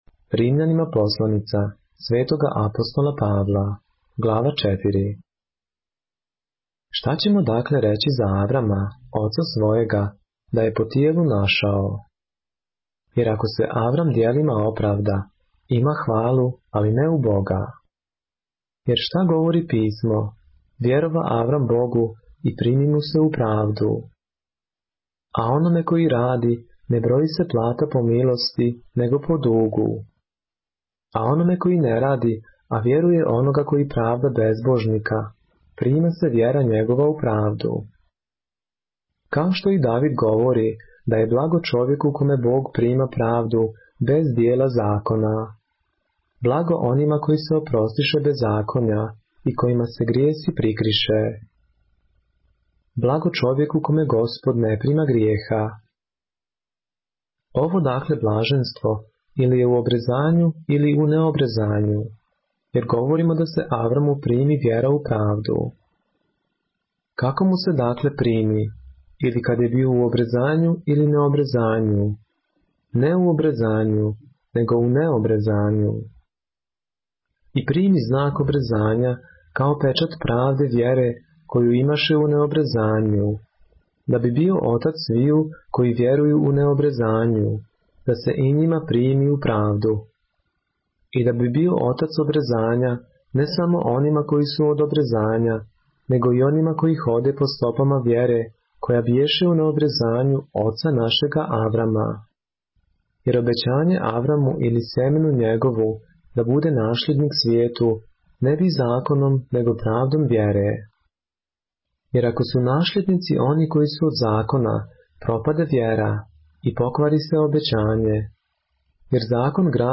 поглавље српске Библије - са аудио нарације - Romans, chapter 4 of the Holy Bible in the Serbian language